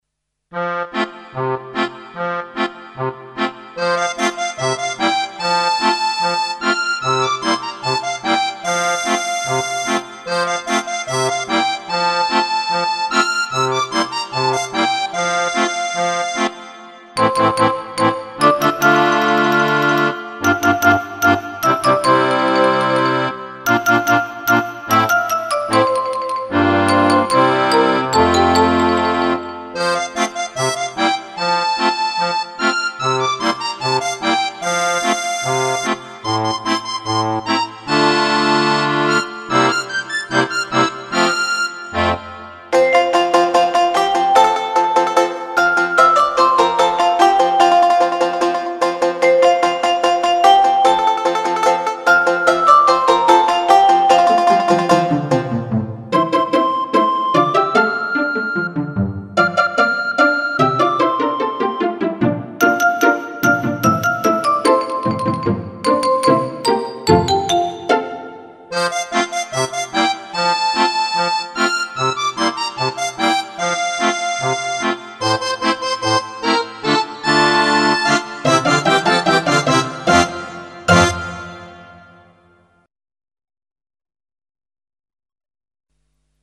玩起了中國童謠與民謠
一幅幅生動的音樂畫面